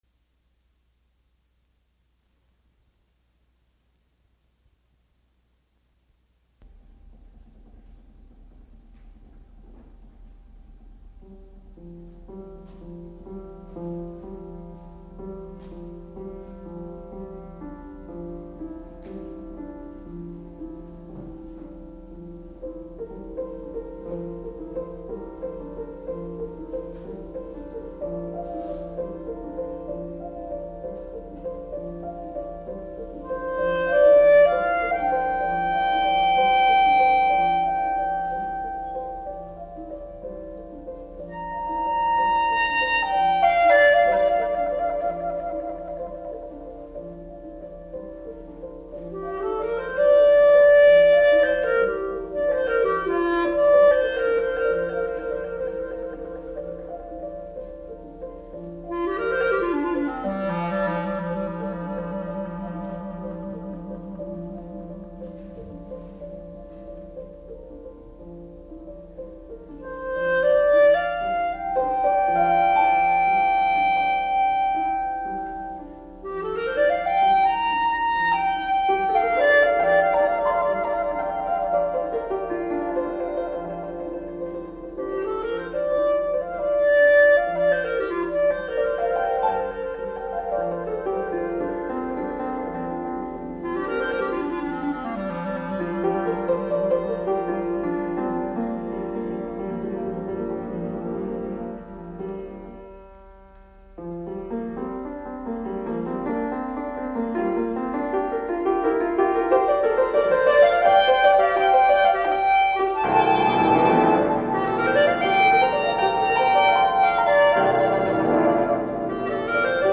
Clarinet and Piano (or string orchestra)